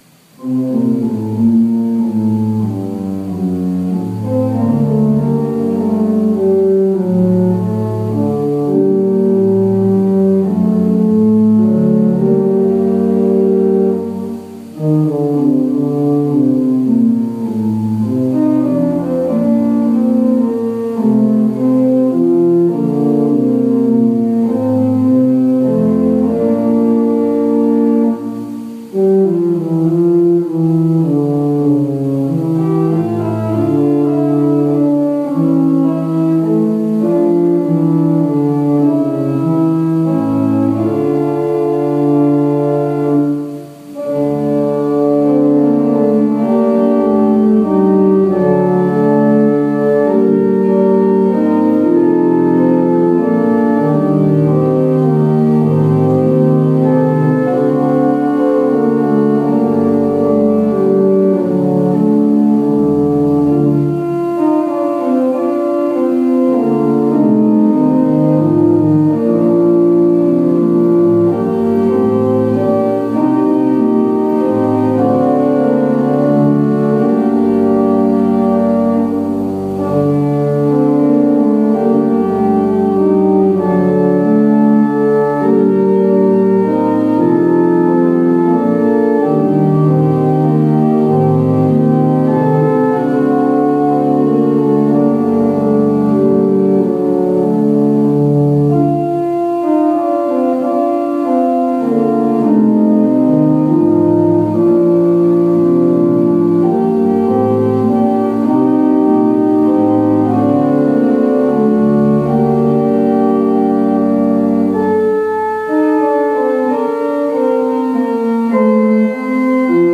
Worship Service September 20, 2020 | First Baptist Church, Malden, Massachusetts